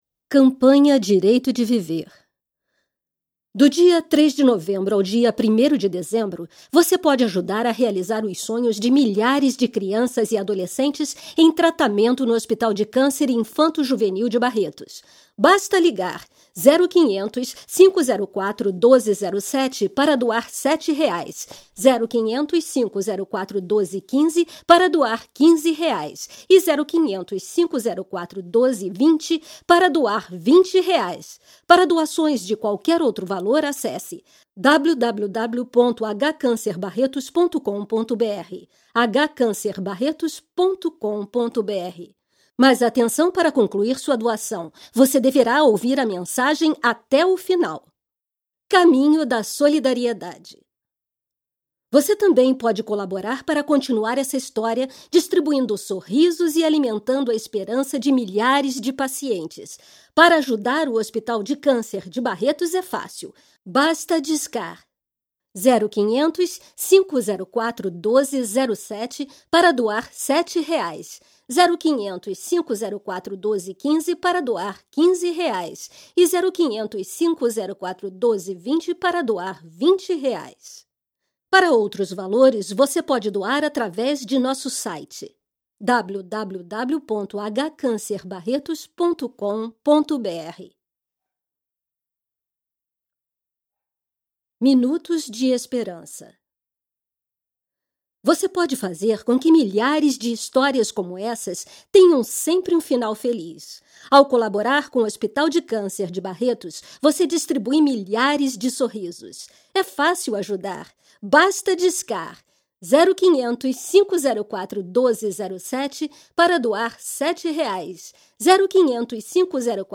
• Feminino
Narração demonstração